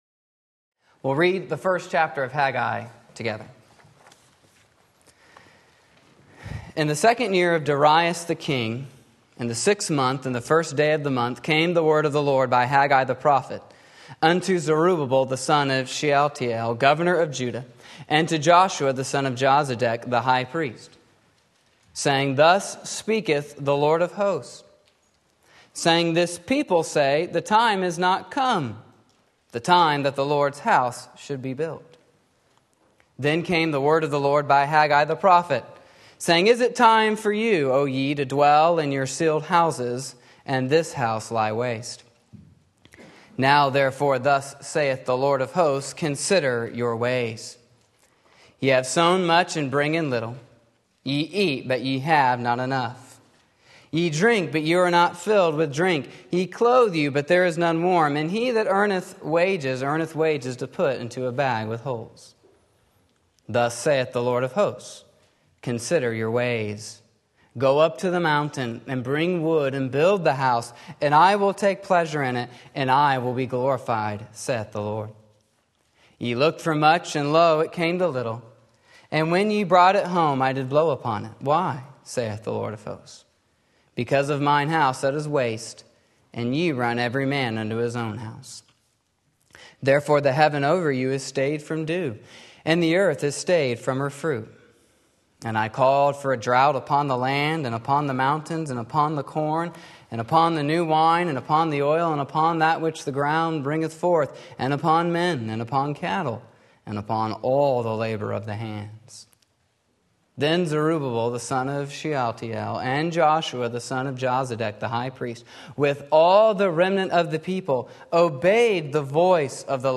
Sermon Link
Putting God First Haggai 1 Sunday Morning Service